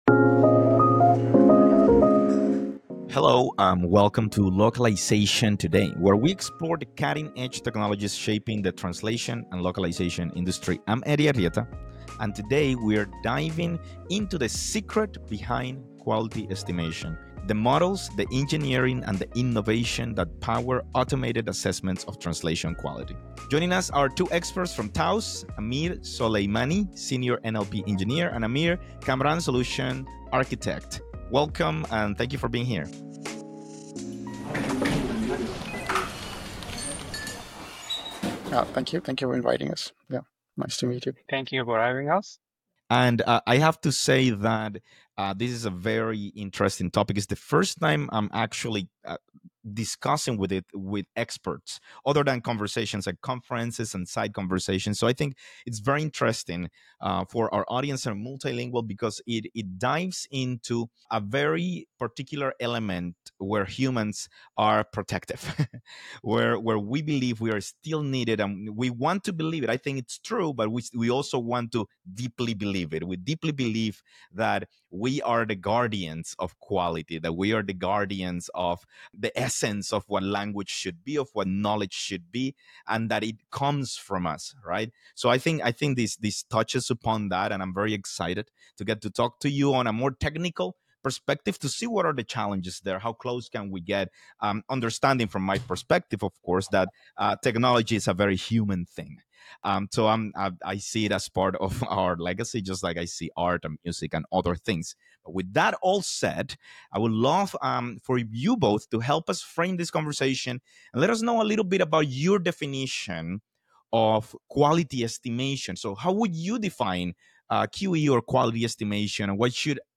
In this conversation, we explore where this matters most (high-volume content, real-time chat, regulated use cases), how QE reshapes workflows after MT, and why language access still demands human oversight in sensitive domains.